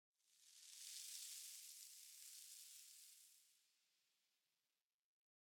Minecraft Version Minecraft Version snapshot Latest Release | Latest Snapshot snapshot / assets / minecraft / sounds / block / sand / sand15.ogg Compare With Compare With Latest Release | Latest Snapshot
sand15.ogg